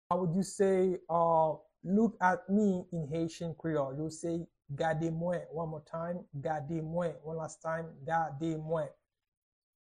How to to say "Look at me" in Haitian Creole - "Gade mwen" pronunciation by native Haitian Creole teacher
“Gade mwen” Pronunciation in Haitian Creole by a native Haitian can be heard in the audio here or in the video below:
How-to-to-say-Look-at-me-in-Haitian-Creole-Gade-mwen-pronunciation-by-native-Haitian-Creole-teacher.mp3